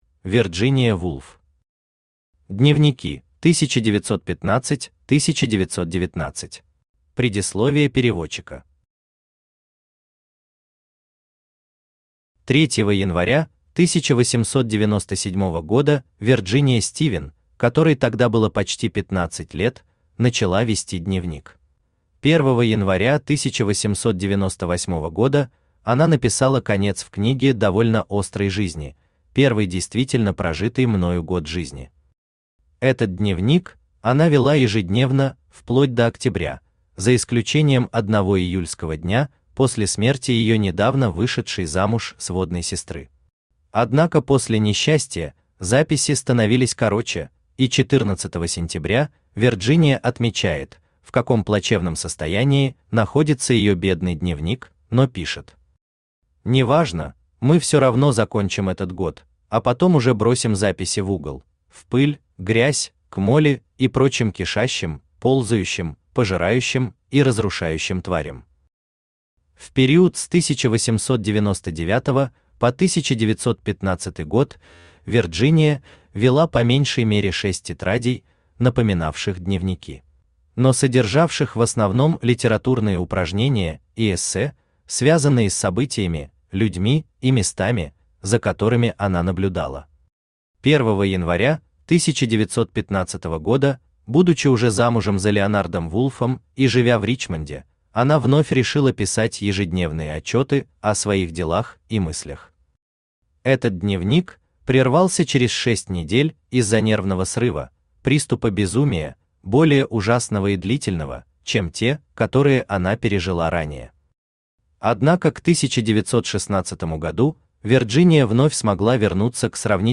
Аудиокнига Дневники: 1915–1919 | Библиотека аудиокниг
Aудиокнига Дневники: 1915–1919 Автор Вирджиния Вулф Читает аудиокнигу Авточтец ЛитРес.